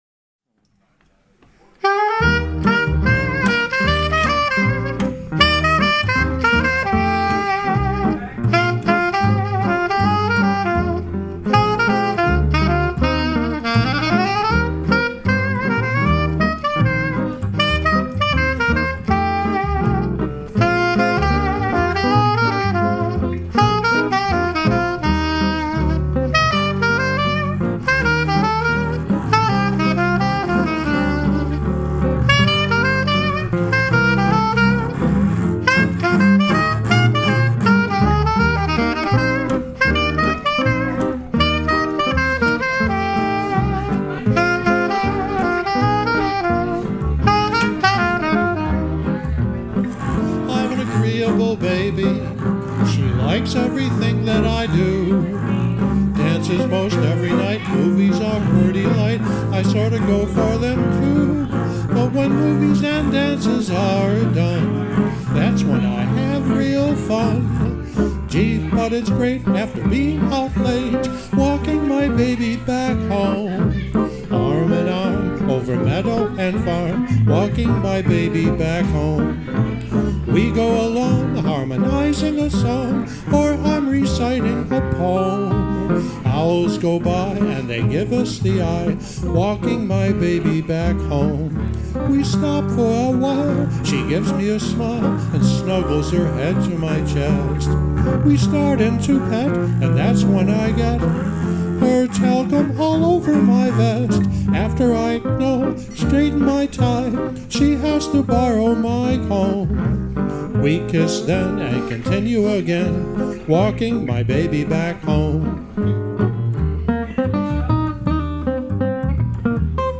I'm very proud of this recent live recording of my band